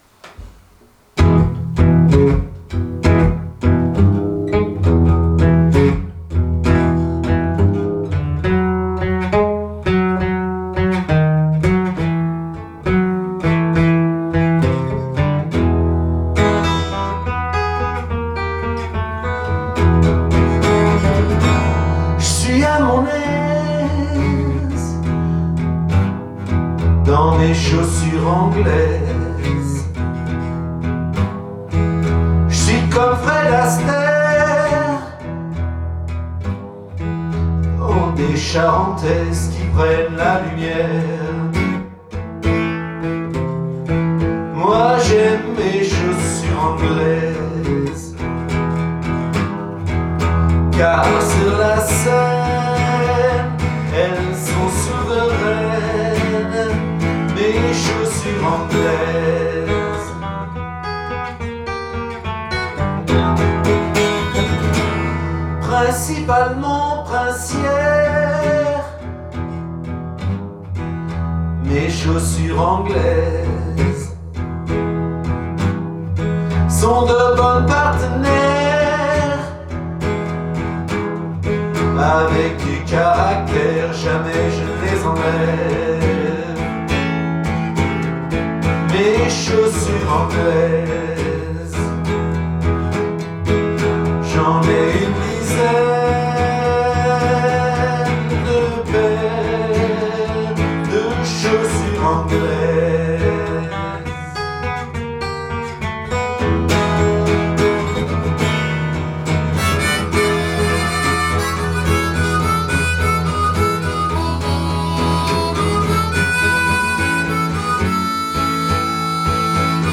West-coast jive dans un décor de cinéma
Un Delta blues